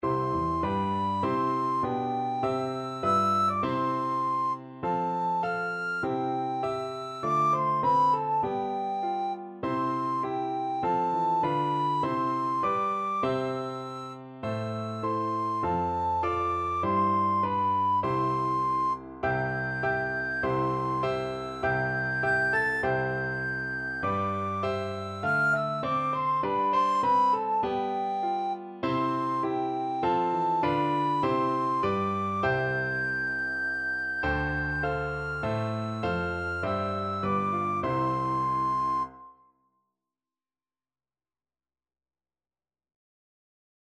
Free Sheet music for Soprano (Descant) Recorder
4/4 (View more 4/4 Music)
Andante
Traditional (View more Traditional Recorder Music)